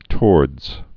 (tôrdz, tə-wôrdz)